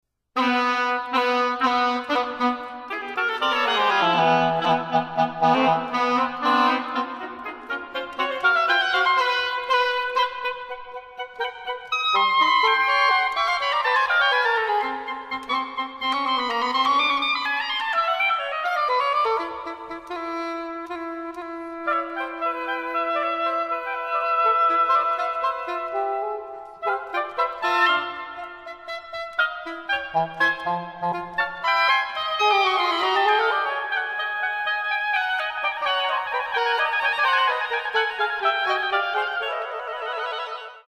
For 2 oboes & English horn.